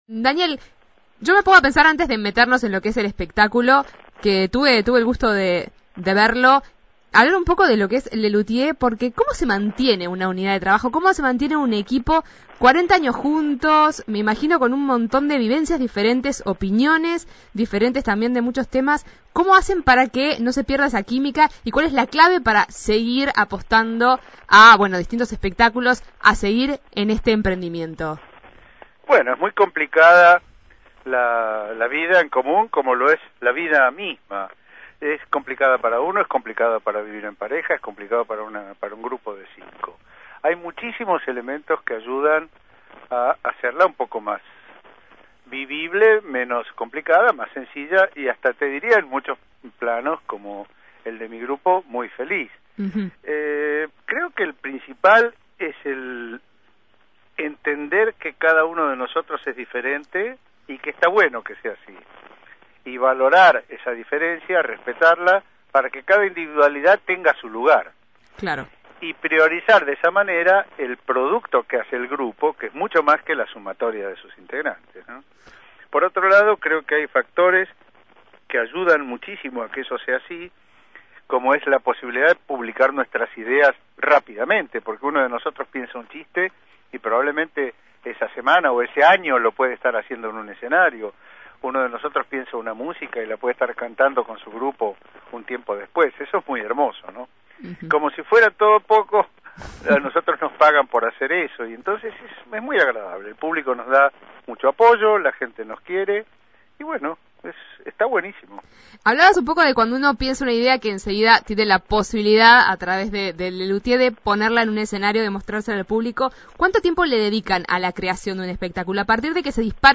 Rabinovich dialogó en la Segunda Mañana de En Perspectiva.